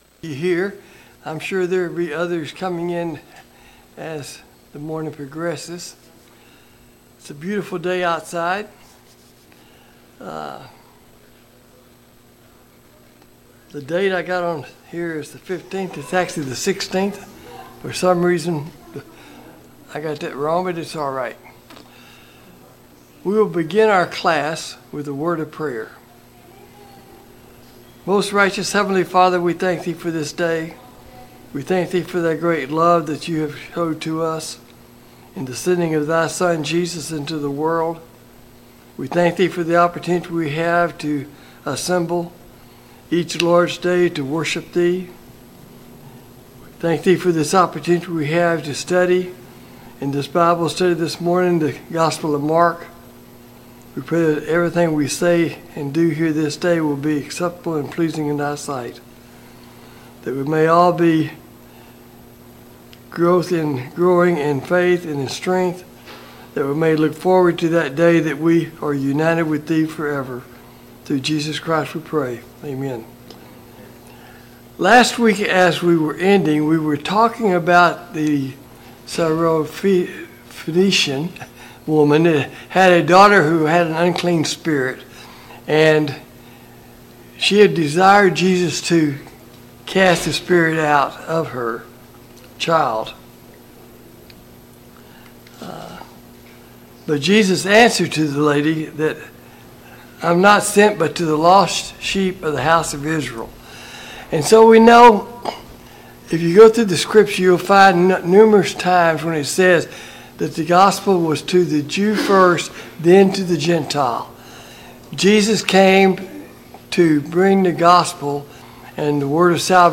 Study of the Gospel of Mark Passage: Mark 8 Service Type: Sunday Morning Bible Class « 9.